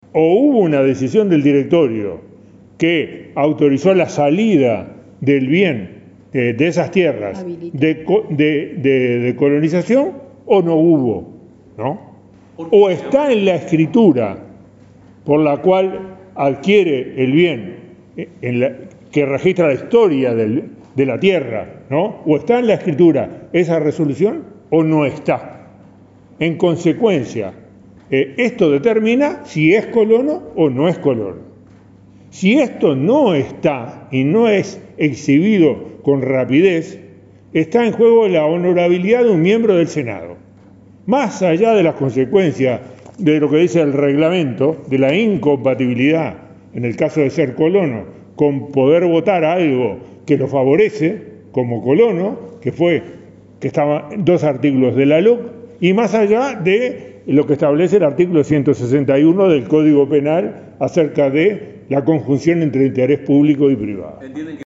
En conferencia de prensa, el senador (FA), Enrique Rubio, aseguró que Manini debe presentar pruebas “con rapidez” porque está en juego la honorabilidad de un miembro del Senado”.